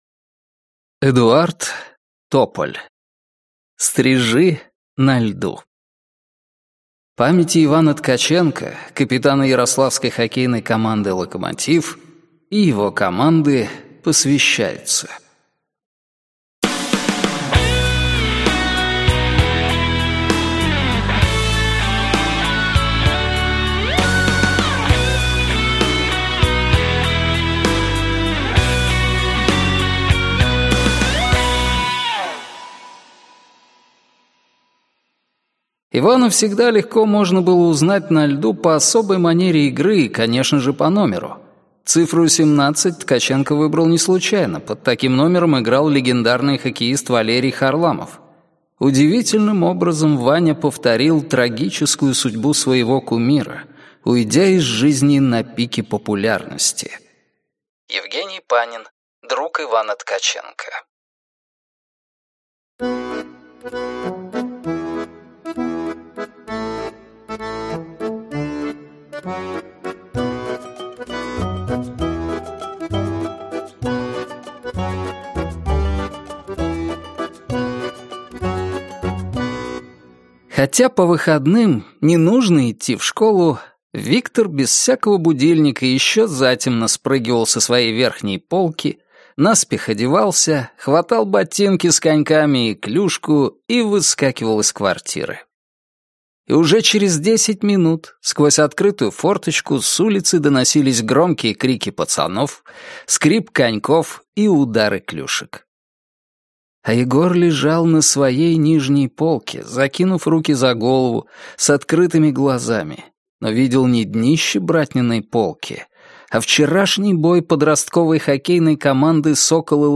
Аудиокнига «Стрижи» на льду | Библиотека аудиокниг